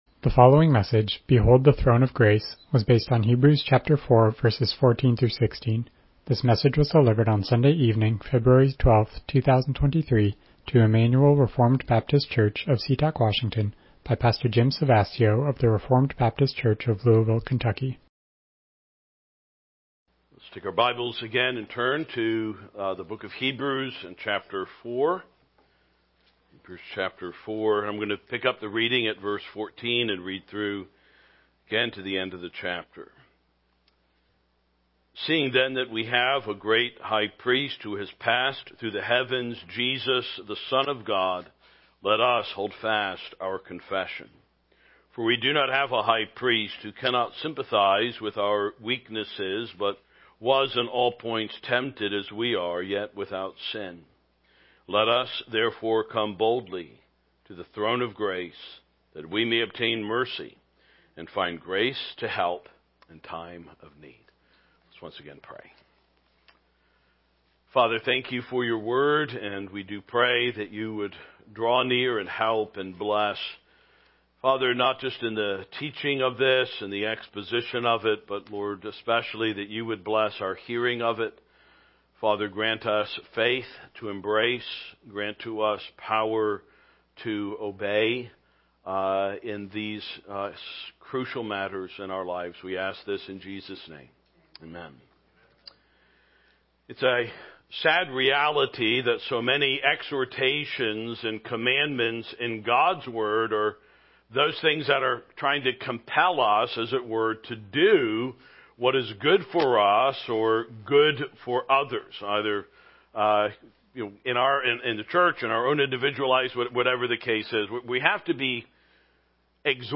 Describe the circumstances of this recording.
Hebrews 4:14-16 Service Type: Evening Worship « Hold Fast to Our Confession LBCF Chapter 20